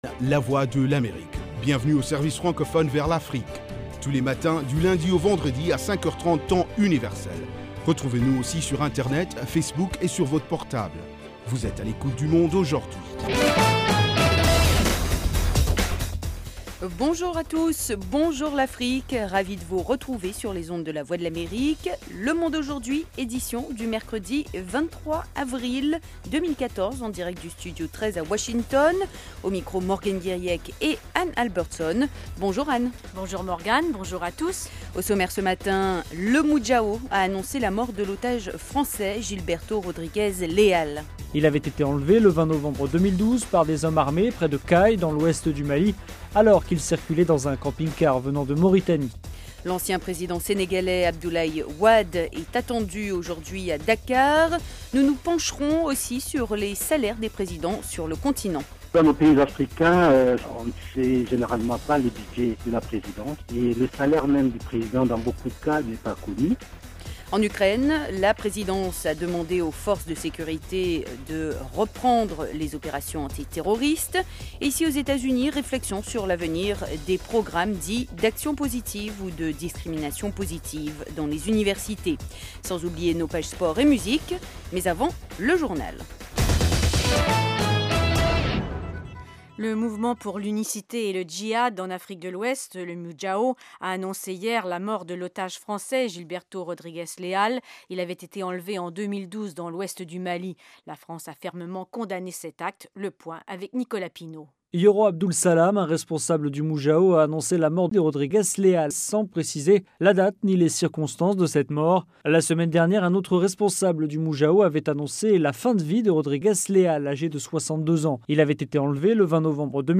Interviews, reportages de nos envoyés spéciaux et de nos correspondants, dossiers, débats avec les principaux acteurs de la vie politique et de la société civile. Aujourd'hui l'Afrique Centrale vous offre du lundi au vendredi une synthèse des principaux développementsdans la région.